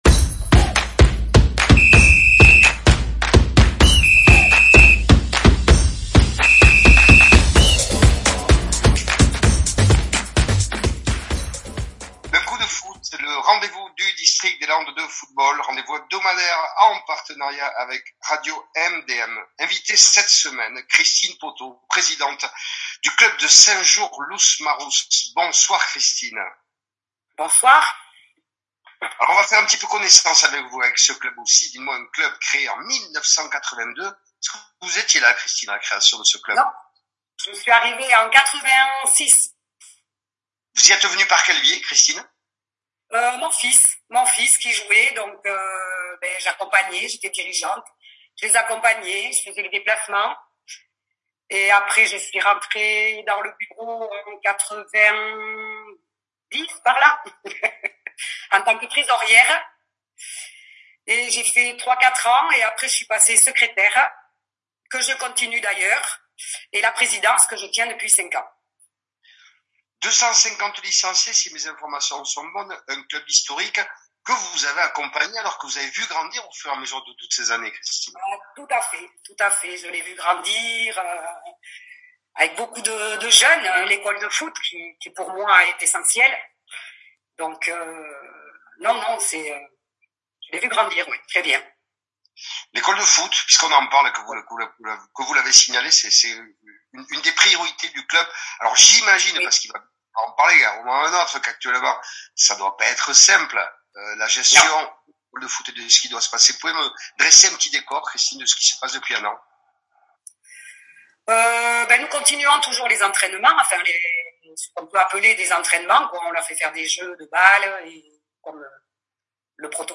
Retrouvez l’interview complète sur le podcast « Un coup de Foot ».